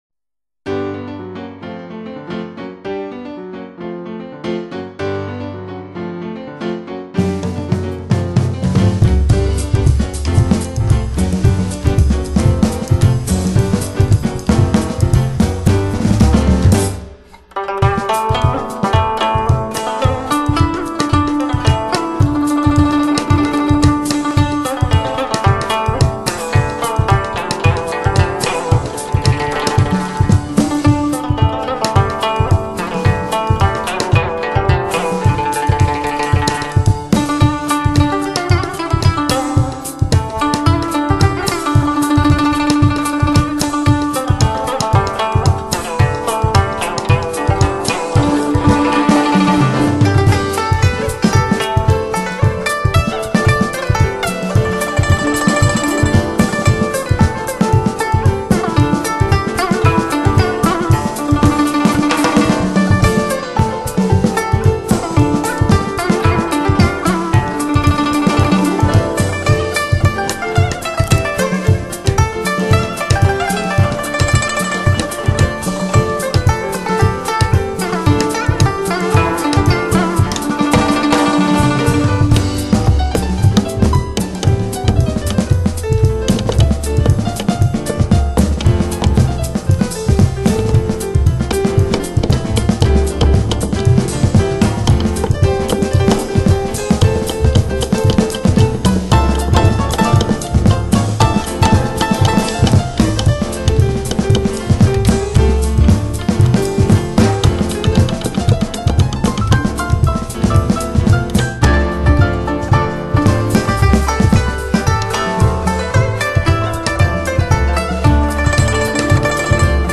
古筝
二胡
琵琶
箫笛
钢琴
贝司
鼓